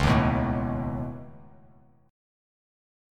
Db7sus2#5 chord